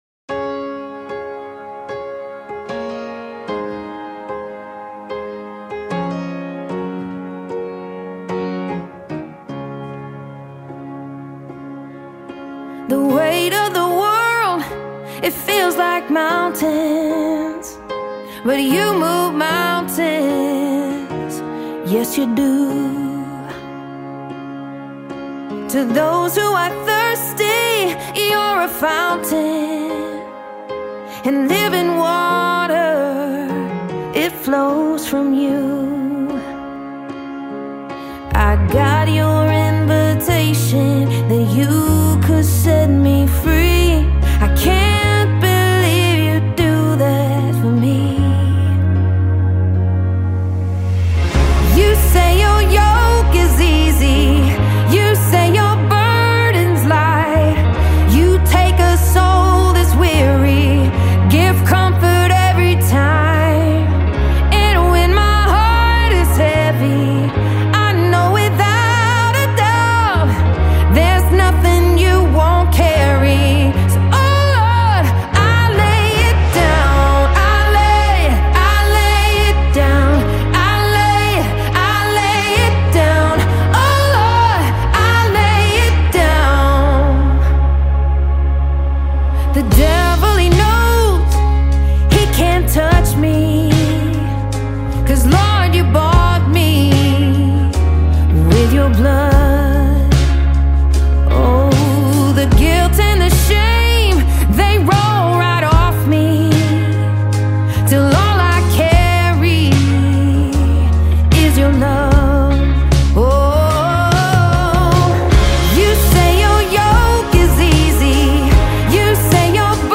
” a soul-saturated pop ballad echoing the promise of Jesus